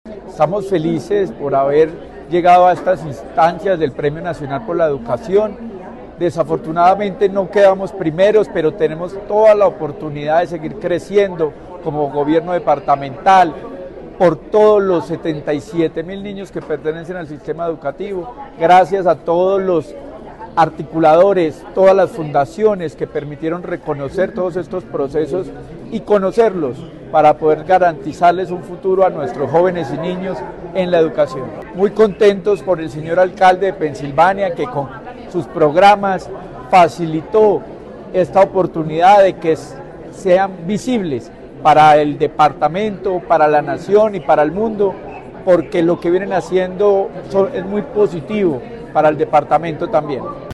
Secretario de Educación de Caldas, Luis Herney Vargas Barrera.
Secretario-de-Educacion-Luis-Herney-Vargas-Barrera-premiacion-Reto.mp3